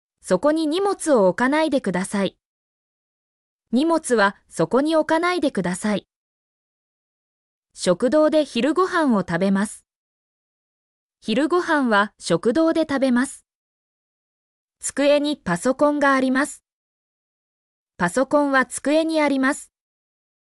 mp3-output-ttsfreedotcom-19_srpIcnLI.mp3